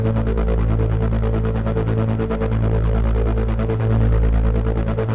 Index of /musicradar/dystopian-drone-samples/Tempo Loops/140bpm
DD_TempoDroneA_140-A.wav